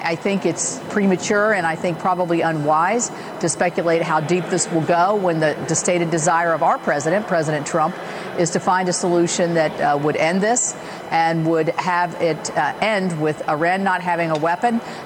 The Israeli-Iran conflict has continued to make headlines, with America sharing intelligence and providing weapons to Israel.  West Virginia’s Republican Senator Shelley Moore Capito told reporters said it remains to be seen if the U.S. will become directly involved in the war…